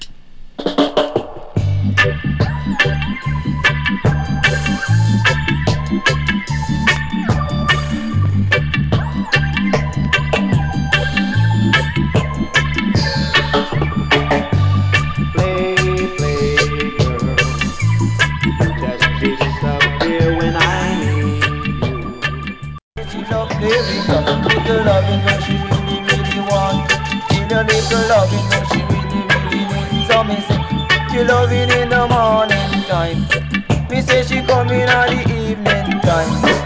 12"/Vintage-Dancehall